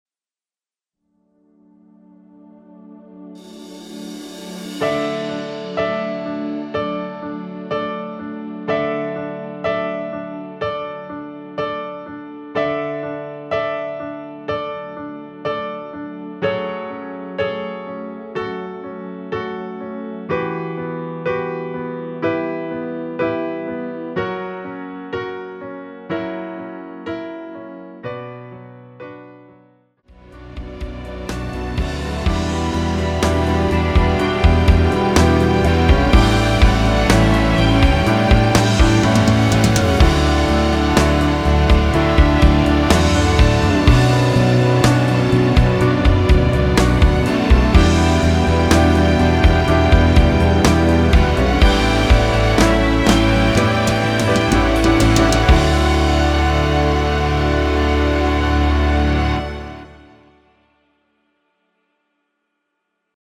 엔딩이 페이드 아웃이라 엔딩을 만들어 놓았습니다.(미리듣기및 가사 참조)
앞부분30초, 뒷부분30초씩 편집해서 올려 드리고 있습니다.
중간에 음이 끈어지고 다시 나오는 이유는